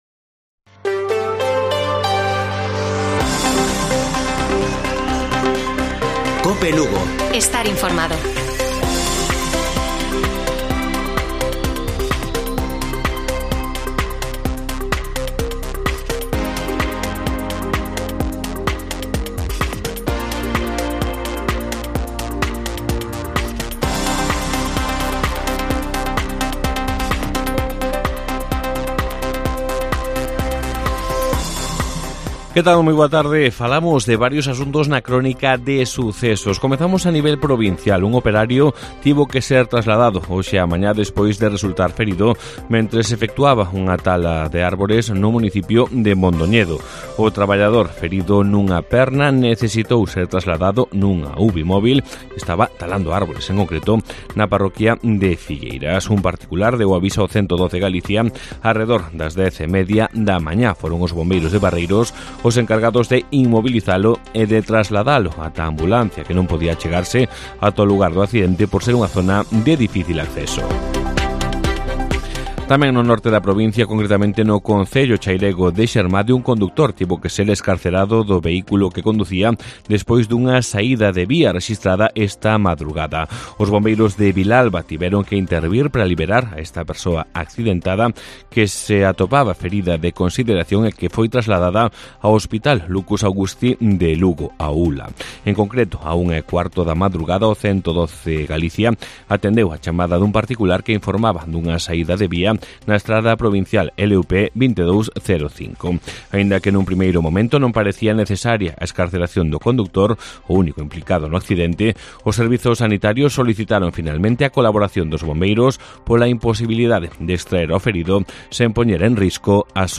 Informativo Mediodía de Cope Lugo. 22 DE AGOSTO. 14:20 horas